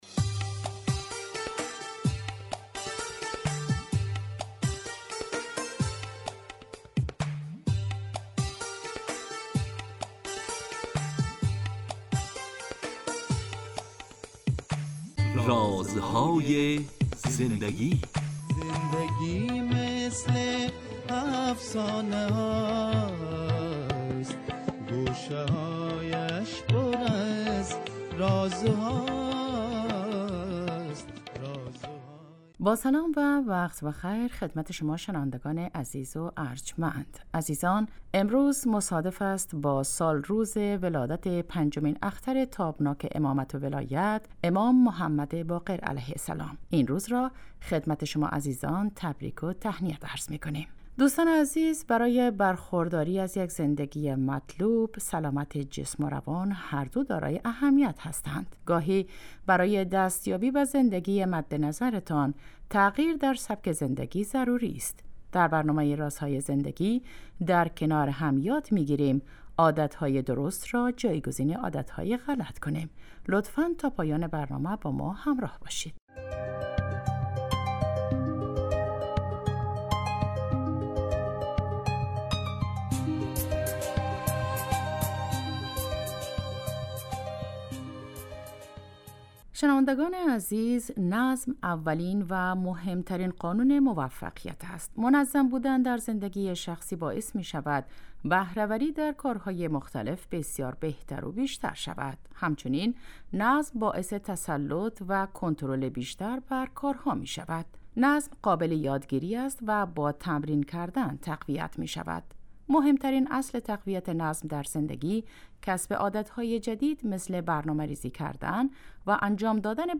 با مجموعه برنامه " رازهای زندگی" و در چارچوب نگاهی دینی به سبک زندگی با شما هستیم. این برنامه به مدت 15 دقیقه هر روز ساعت 10:35 از رادیو دری پخش می شود .